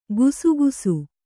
♪ gusugusu